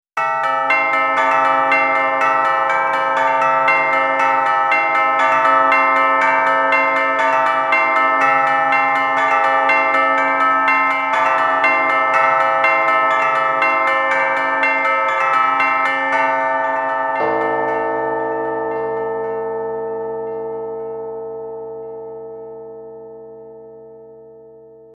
18. Звучит звук колокола…